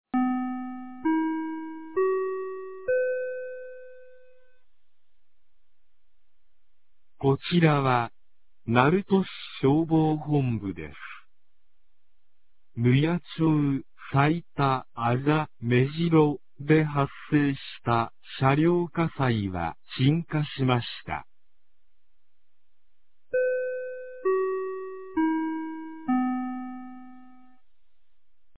2025年03月08日 23時02分に、鳴門市より大麻町-川崎、大麻町-津慈、大麻町-萩原、大麻町-板東、大麻町-桧へ放送がありました。